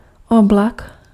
Ääntäminen
Synonyymit mrak Ääntäminen Tuntematon aksentti: IPA: /oblak/ IPA: /ɔblak/ Haettu sana löytyi näillä lähdekielillä: tšekki Käännös Ääninäyte 1. nembo {m} 2. nuvola {f} 3. nube {f} Suku: m .